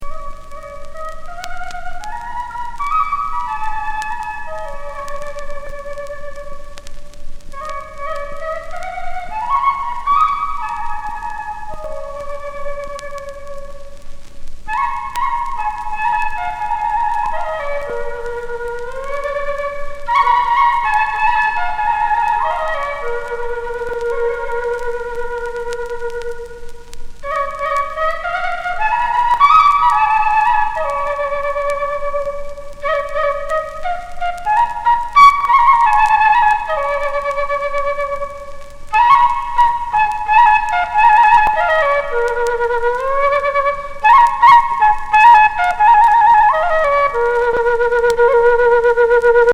南米高山地帯の楽器「ケーナ」を使用した一枚。
特殊歌唱が不思議感漂うエスノな